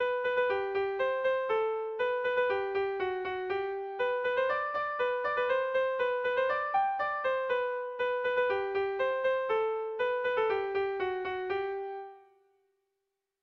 Irrizkoa
ABDAB